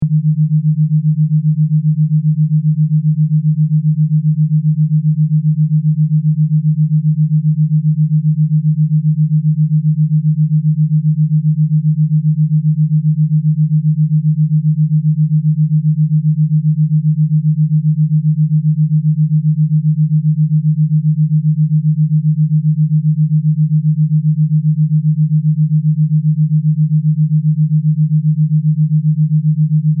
Binaural beats work by sending slightly different sound wave frequencies to each ear, guiding your brain into specific states through brainwave frequency entrainment.